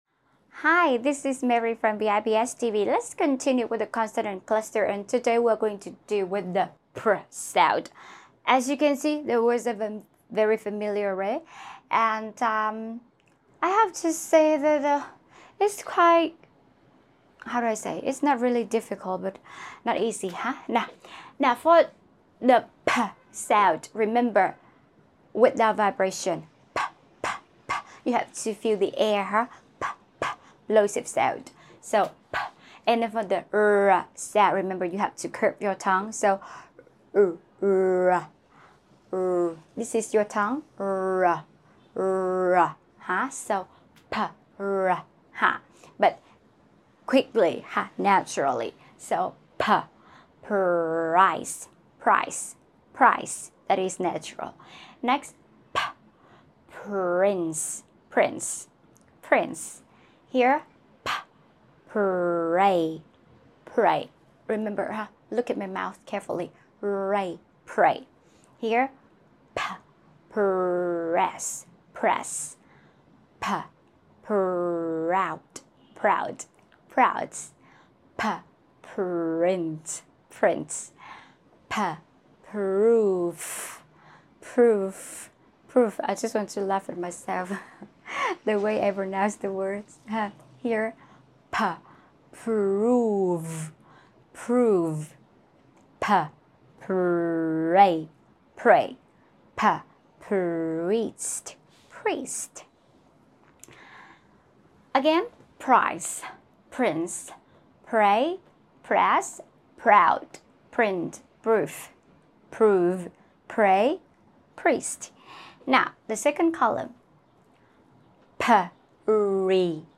How to pronounce pr sound sound effects free download
How to pronounce pr sound in English | consonant blend | consonant cluster | IPA